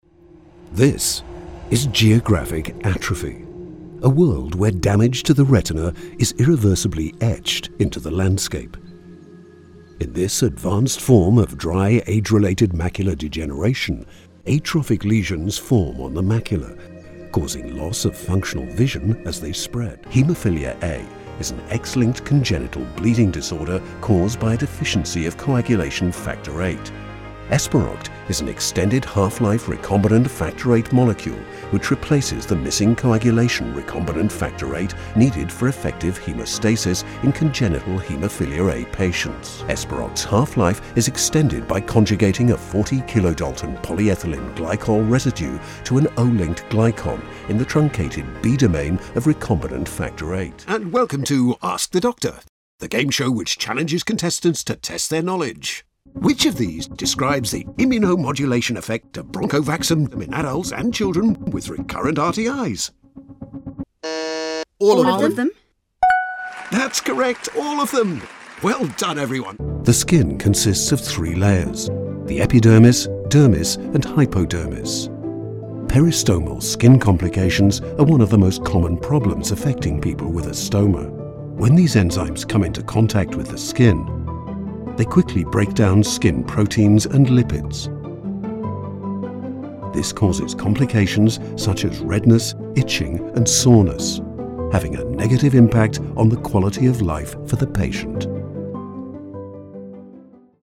Narração Médica
Minha voz é geralmente descrita como calorosa, natural e distinta e é frequentemente usada para adicionar classe e sofisticação a projetos de publicidade e narrativa.